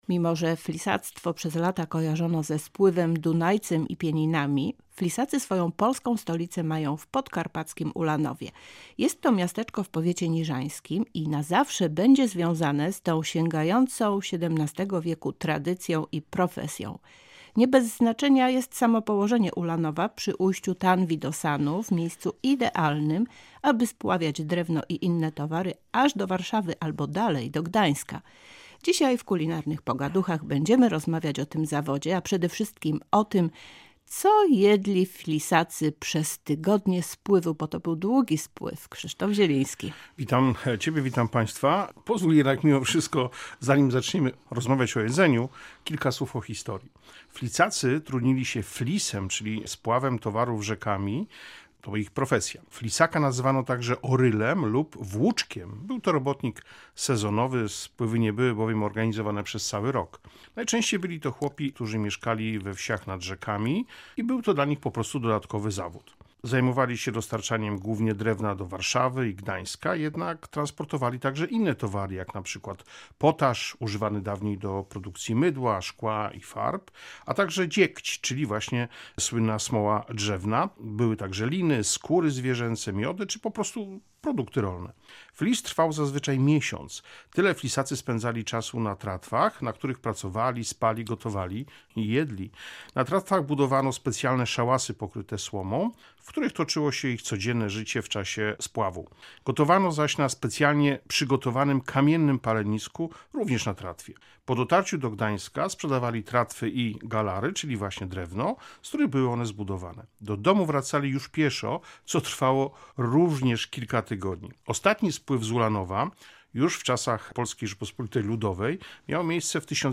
Całej rozmowy